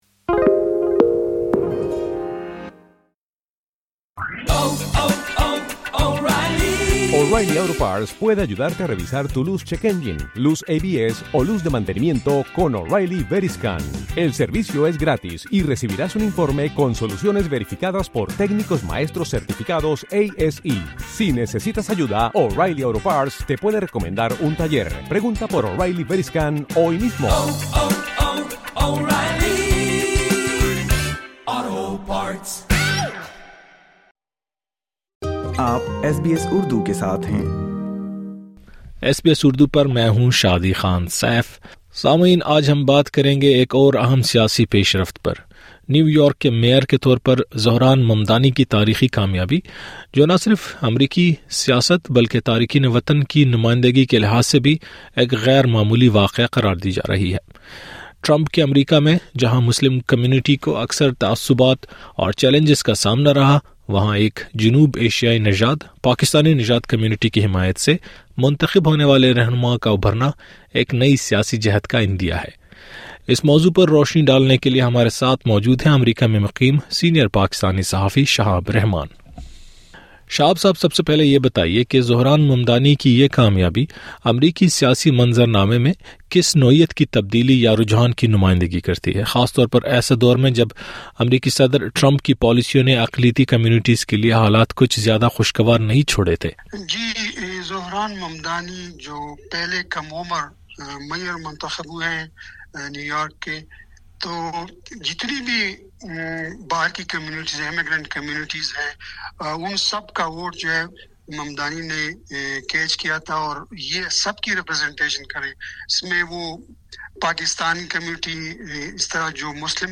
آج کے ایس بی ایس اردو پوڈکاسٹ میں ہمارے ساتھ ہیں امریکہ میں مقیم پاکستانی صحافی،